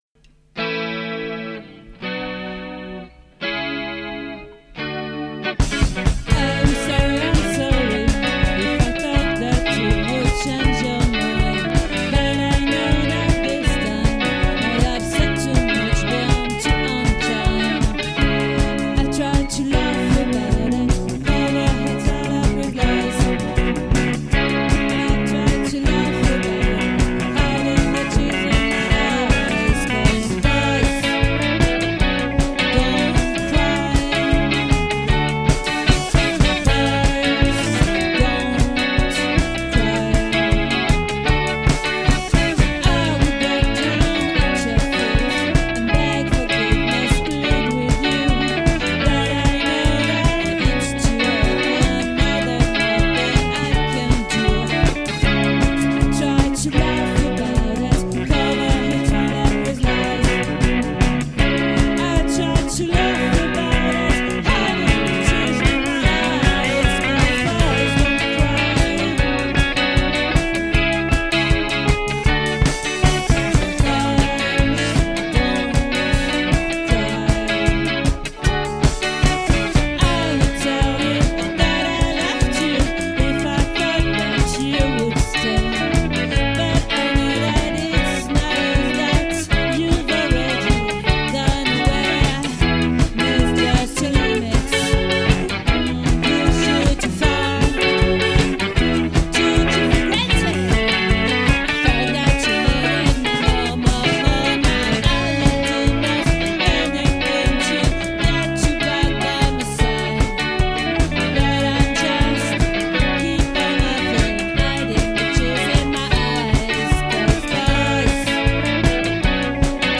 🏠 Accueil Repetitions Records_2022_10_05_OLVRE